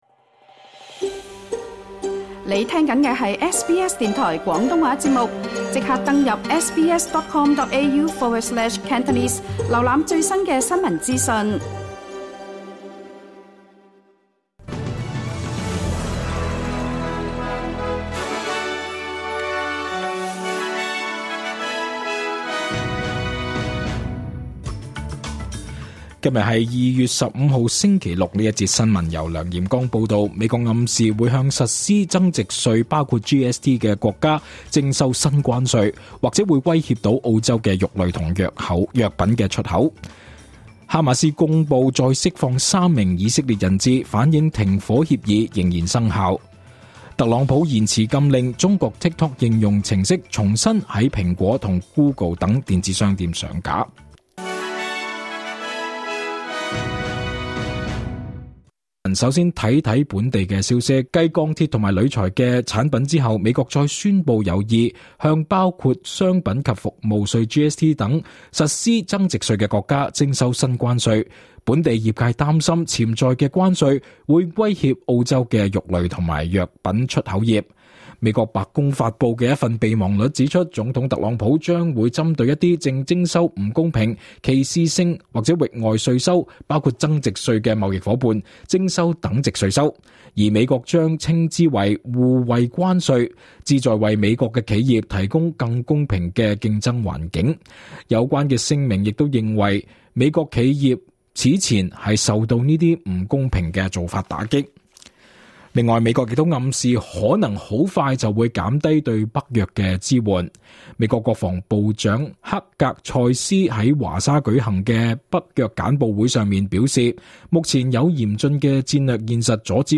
2025 年 2 月 15 日 SBS 廣東話節目詳盡早晨新聞報道。